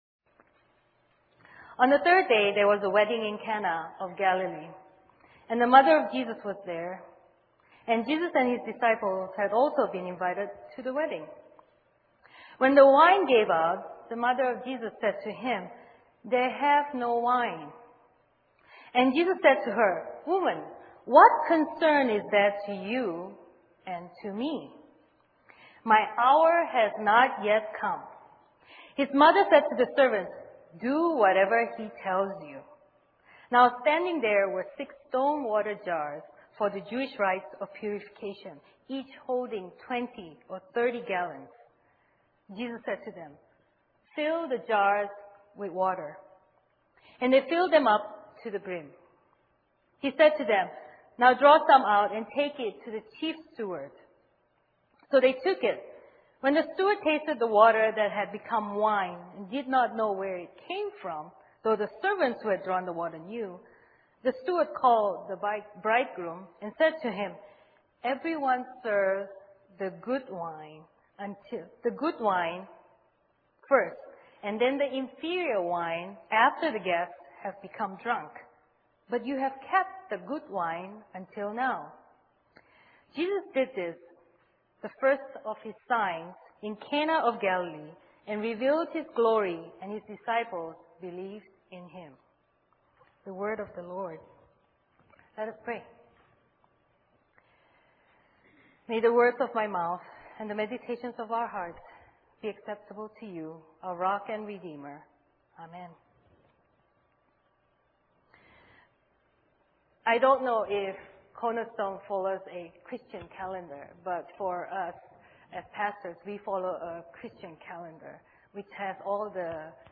Cornerstone Church